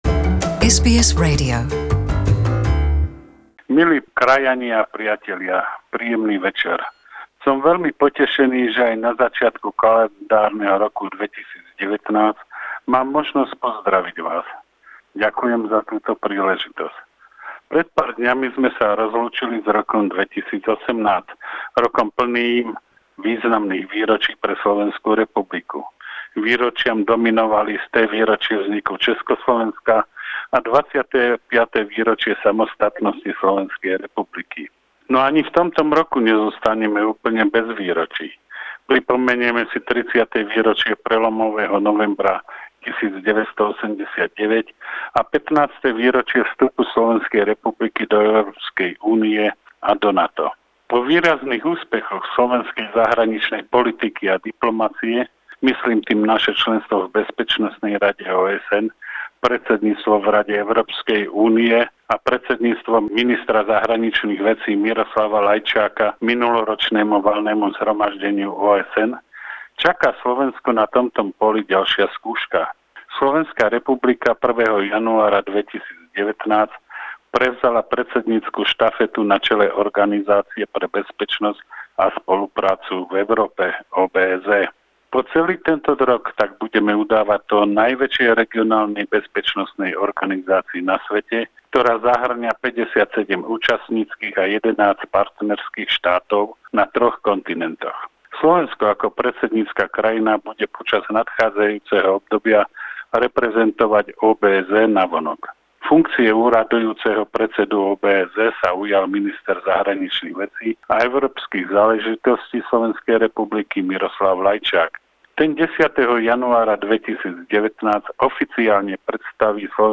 Novoročný príhovor lúčiaceho sa veľvyslanca SR v Canberre Igora Bartha